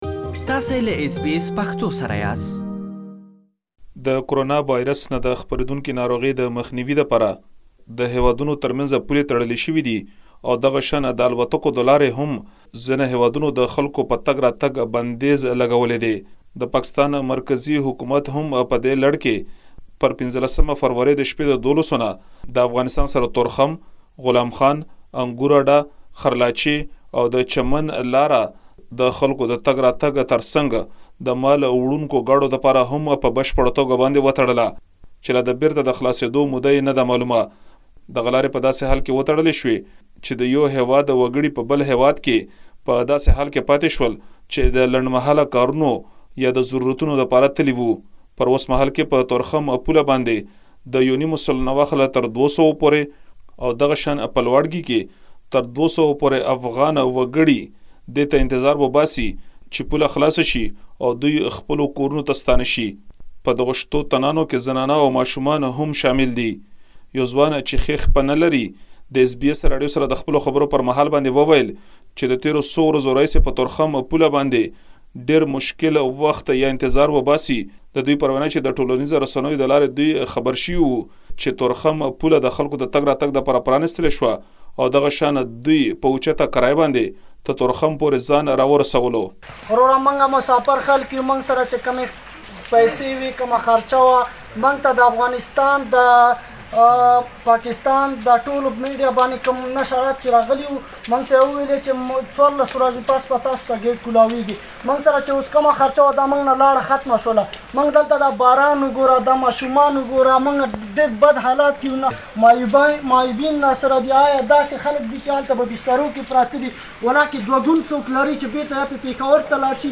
د کرونا ويروس وبا د کرښې دواړو خواوو ته ايسار مسافر له ګڼو ستونزو سره مخ کړي او بيلابيلو ځايونو کې سلګونه کسان چې پکې ښځې او ماشومان هم شامله دي د سر پناه او خوراک نه شتون سره انتظار کوي ترڅو ورته د سفر کولو اجازه ورکړل شي. زمونږ همکار د کرښې دواړه خواوو ته ايسارو کسانو سره خبرې کړي او د هغوی حالت يې راخيستې چې تاسې دا ټولې خبرې په تيار شوي رپوټ کې اوريدلی شئ.